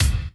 JB KICK 4.wav